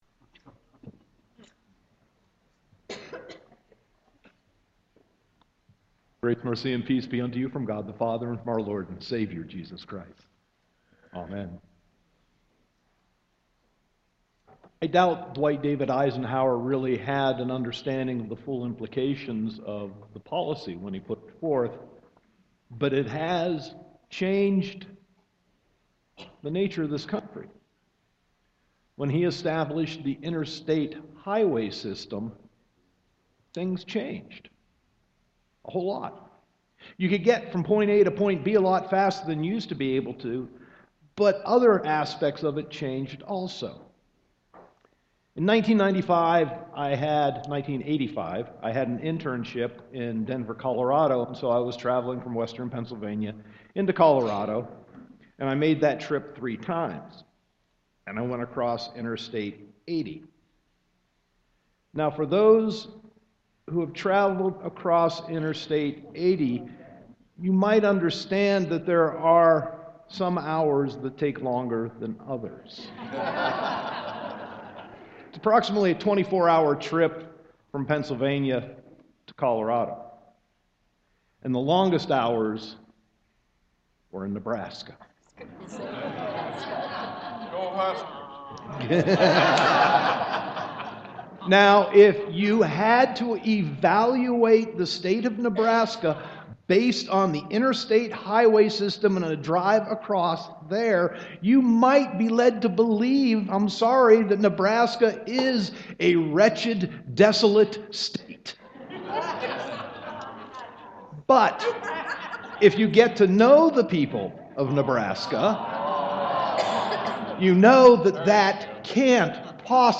Sermon 1.31.2016